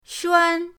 shuan1.mp3